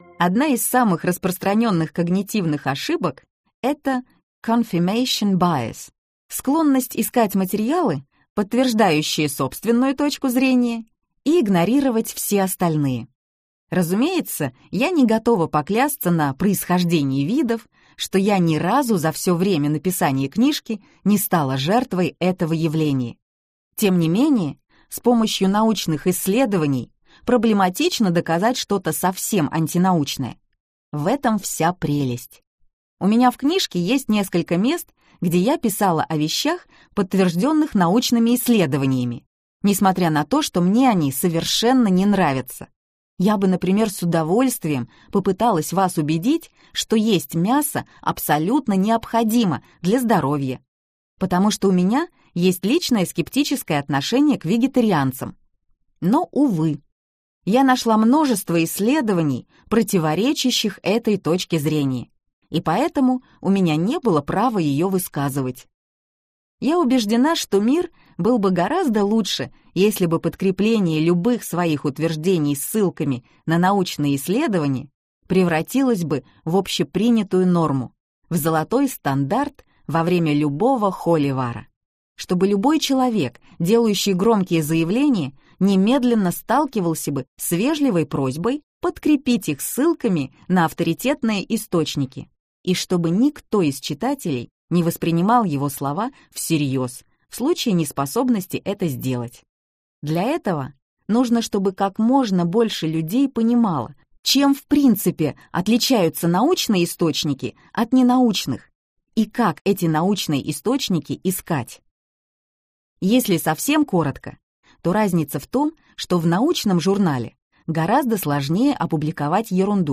Аудиокнига В интернете кто-то неправ! Научные исследования спорных вопросов - купить, скачать и слушать онлайн | КнигоПоиск